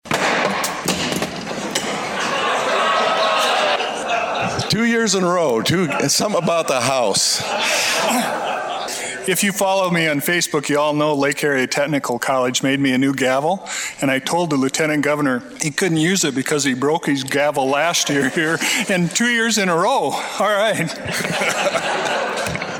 The old gavel broke creating a light-hearted moment prompting some teasing from Bartels as Rhoden began a joint session of the South Dakota Legislature, just ahead of Governor Kristi Noem’s 2023 Budget Address.